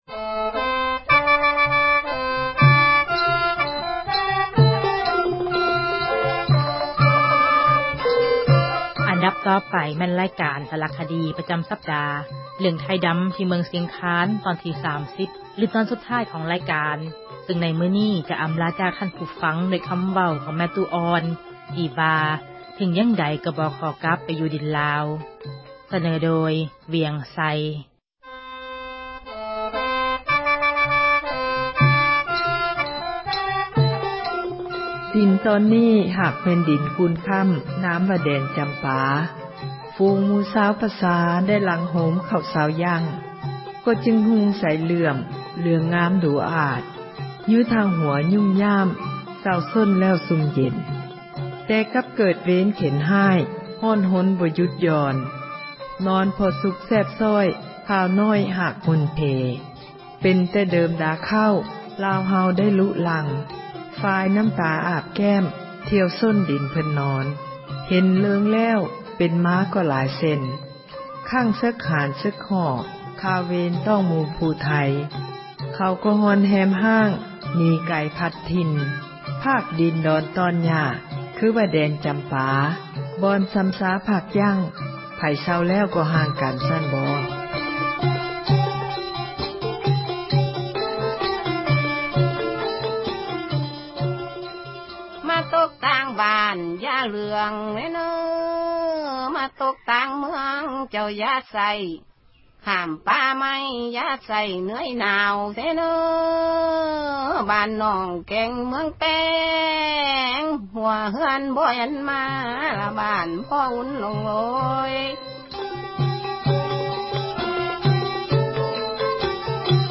ສາຣະຄະດີ